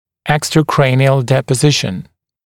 [ˌekstrə’kreɪnɪəl ˌdepə’zɪʃn] [ˌdiːpə-] [ˌэкстрэ’крэйниэл ˌдэпэ’зишн] [ˌди:пэ-] наружное черепное отложение (кости)